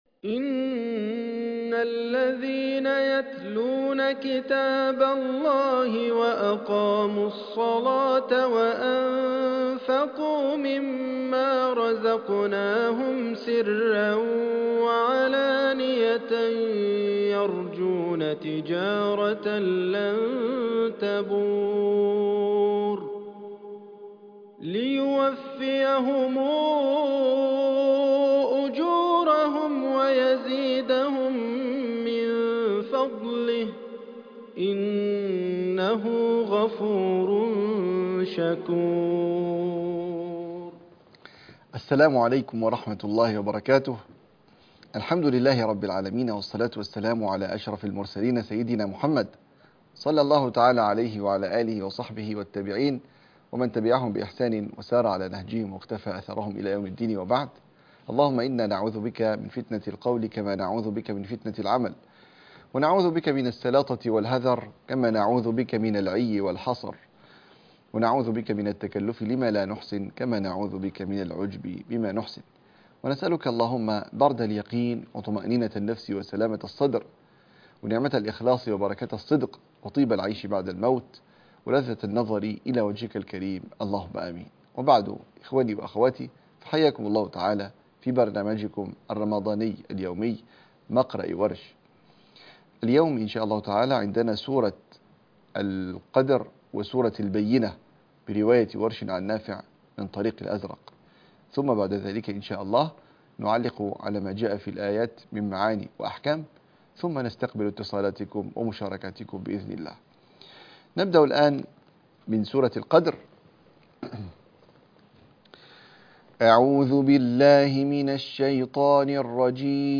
مقرر التلاوة سورة القدر - البينة -الحلقة الثامنه -مقرأ ورش 2